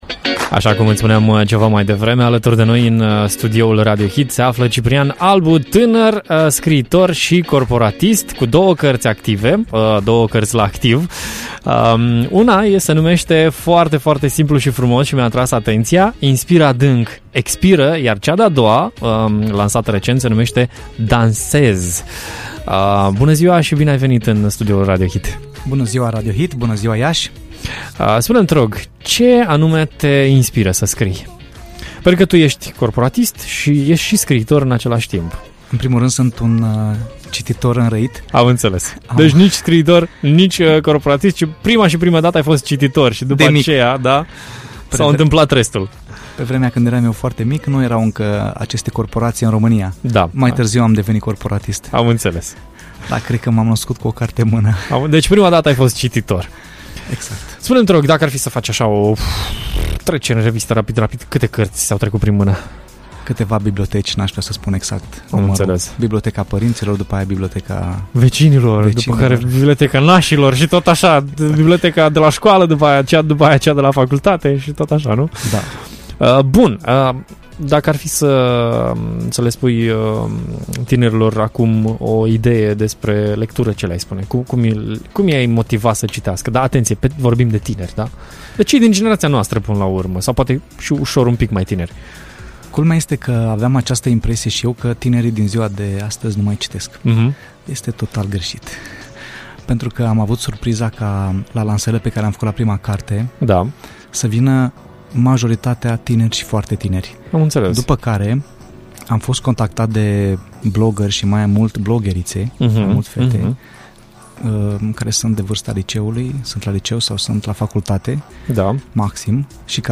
am aflat în direct la Radio Hit